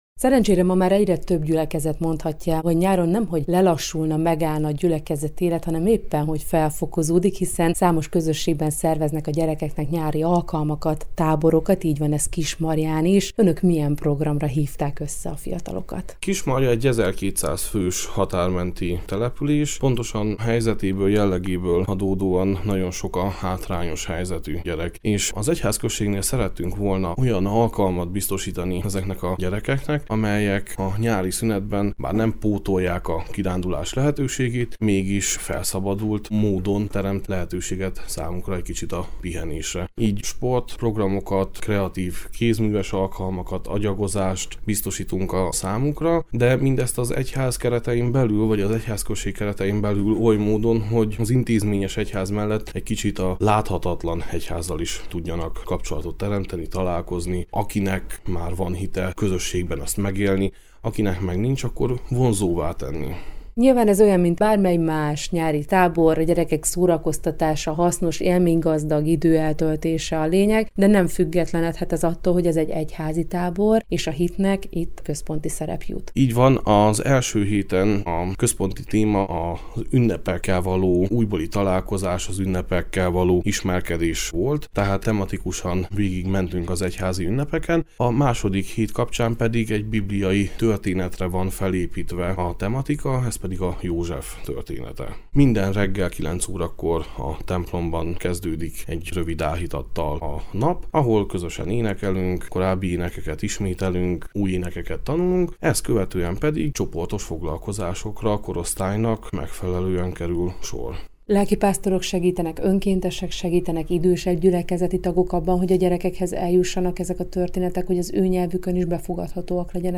A teljes interjú itt hallgatható meg: